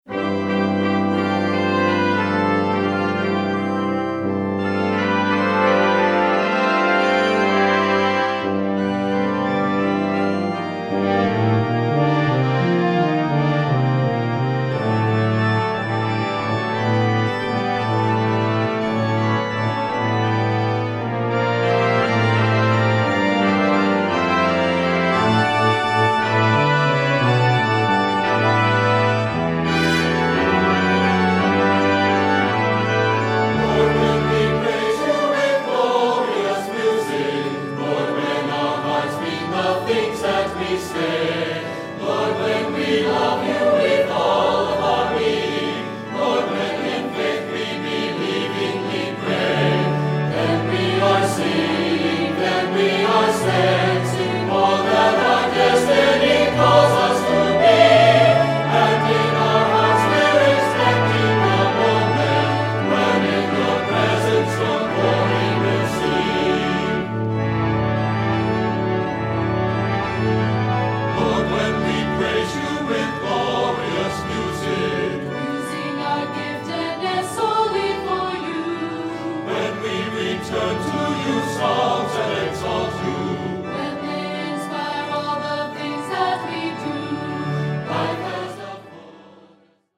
Choral Church
SAB